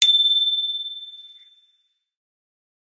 bicycle-bell_02
bell bells bicycle bike bright chime chimes clang sound effect free sound royalty free Memes